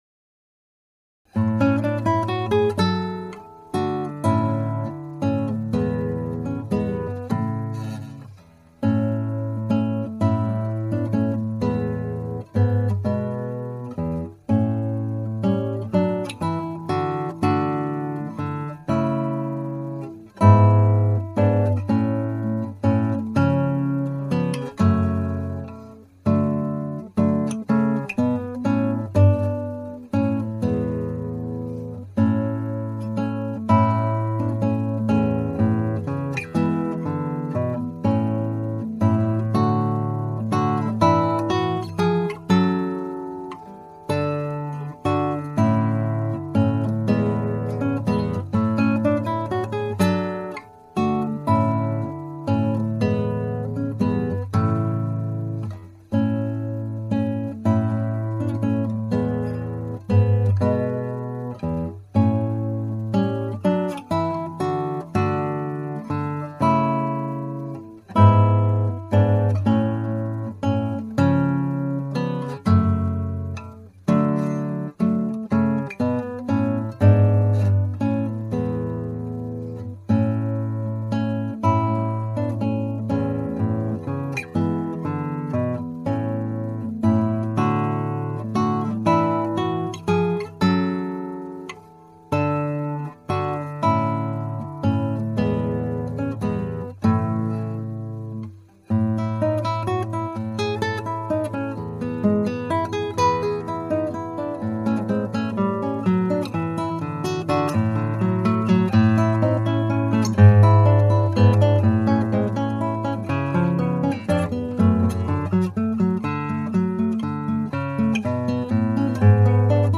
ギター演奏ライブラリ
(アマチュアのクラシックギター演奏です [Guitar amatuer play] )
テンポも少し上げました。
中間部カデンツァも大分、上下の旋律が聞こえるようになってきました。
和音の連続でかなり音が鳴り切っていない。また中間部はミスも力で通してしまっている。